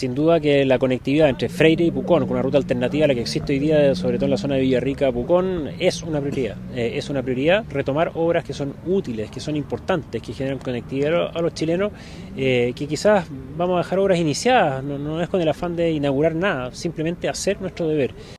En conversación con La Radio, el próximo ministro de Obras Públicas, Martín Arrau, señaló que el proyecto de construcción de una doble vía entre Freire y Pucón será una prioridad para su gestión, donde espera retomar una agenda de obras estratégica.